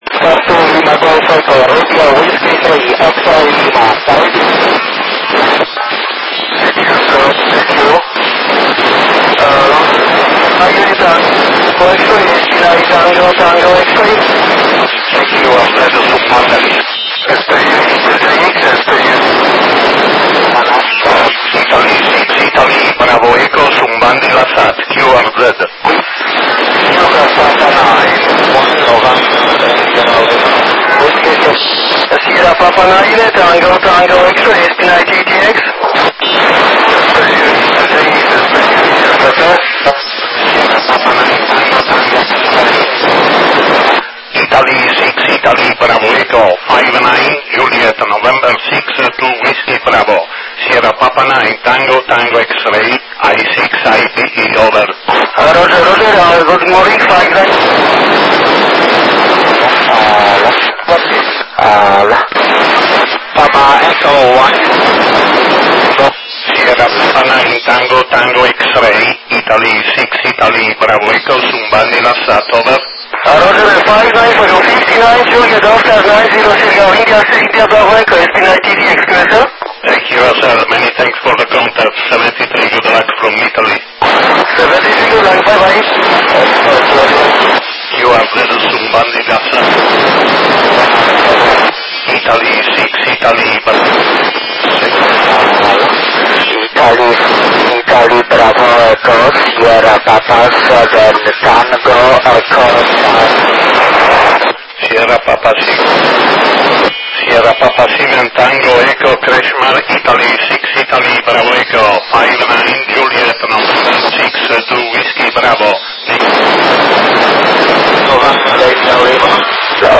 uplink 145,795 Mhz , downlink 435,345 Mhz FM, 233,6 Sub-Tone
Trasponder VHF/UHF - Ripetitore PARROT